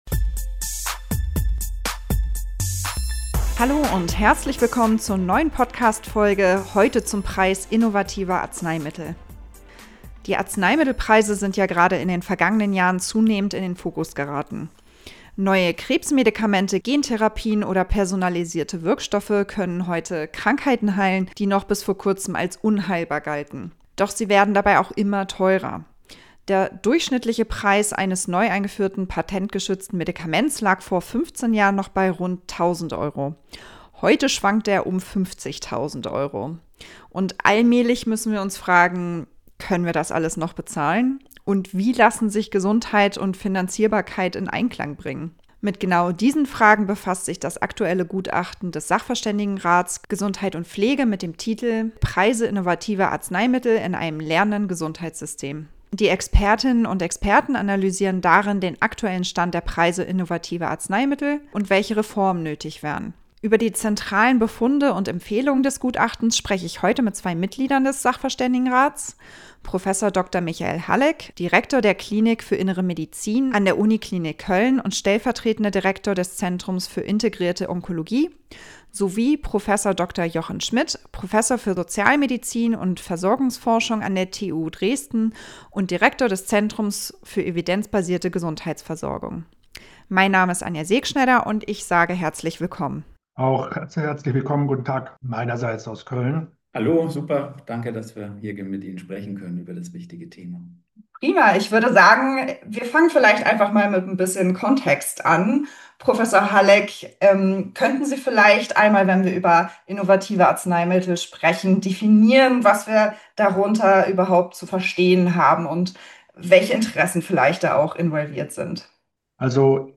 01:02 Vorstellung der Interviewpartner